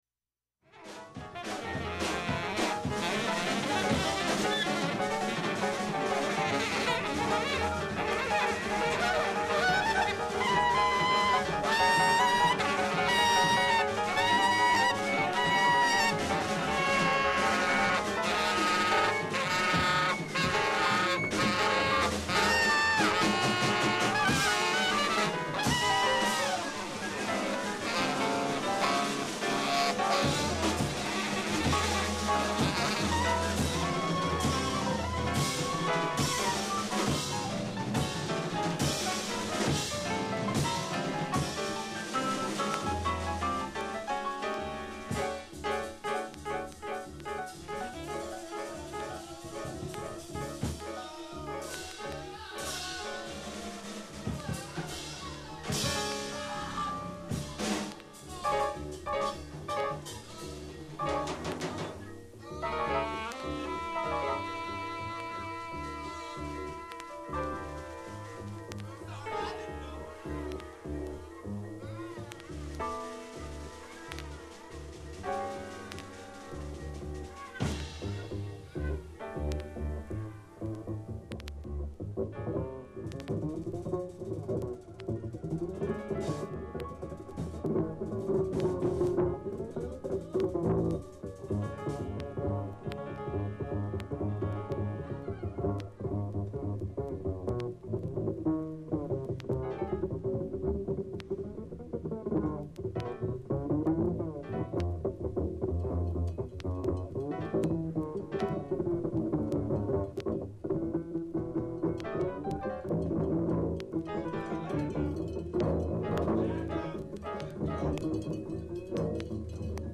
ほかきれいで、良い音質です。
アフロパーカッション スピリチュアルジャズレア盤